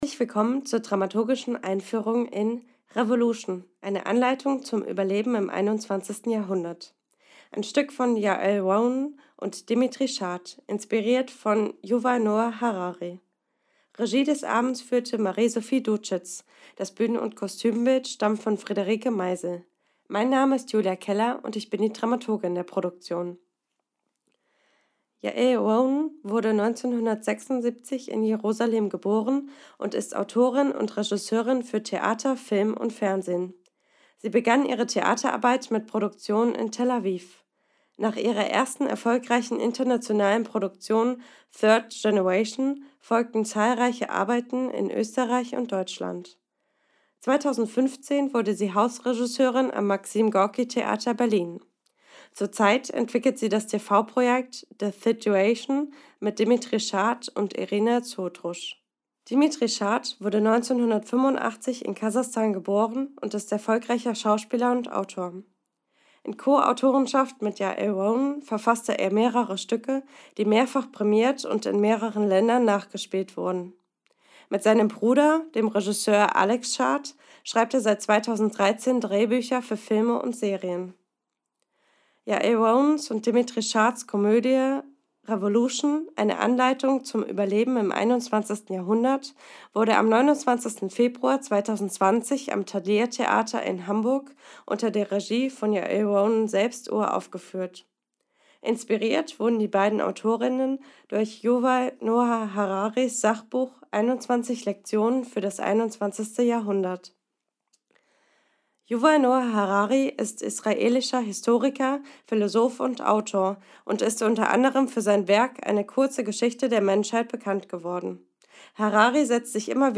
Einführung (R)Evolution